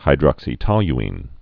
(hī-drŏksē-tŏly-ēn)